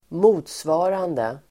Uttal: [²m'o:tsva:rande]